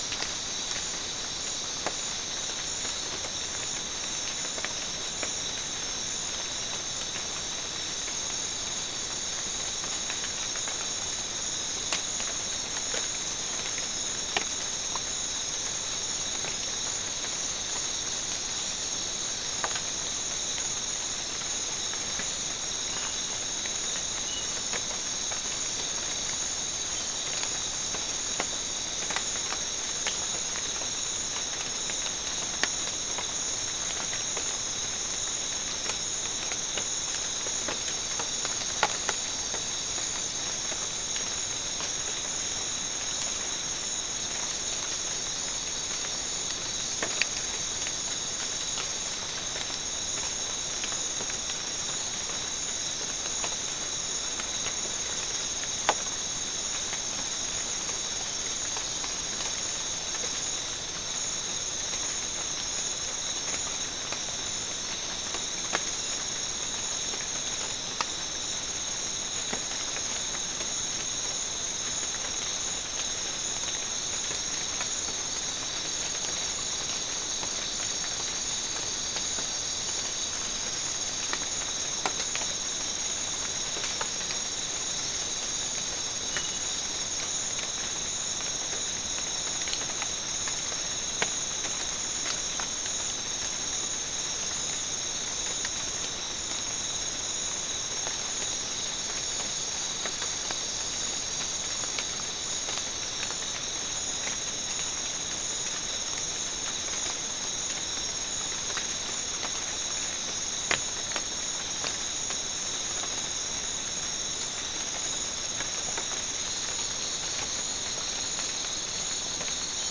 Soundscape Recording Location: South America: Guyana: Sandstone: 1
Recorder: SM3